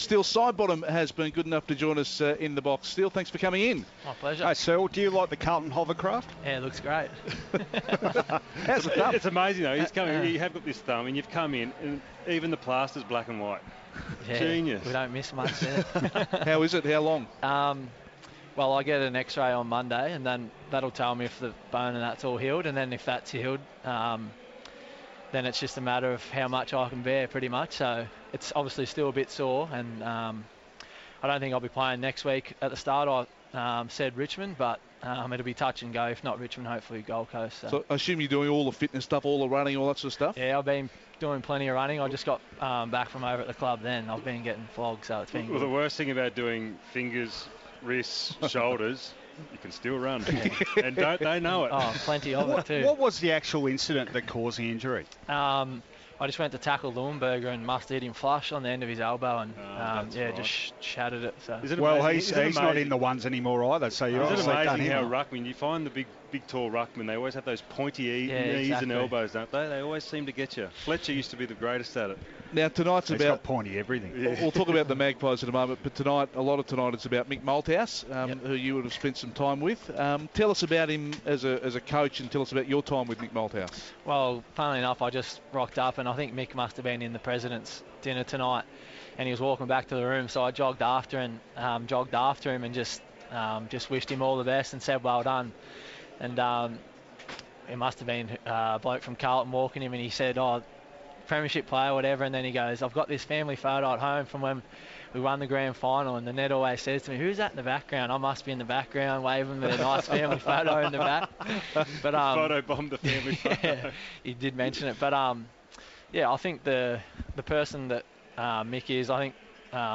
Injured Collingwood star Steele Sidebottom chats ahead of the Pies' Friday night clash with Carlton.